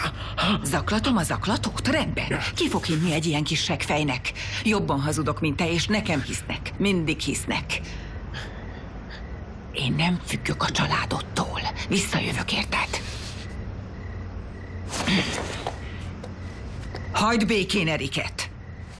A színésznő zseniális játéka és hangjának frissessége ezt a reakciót váltotta ki belőlem.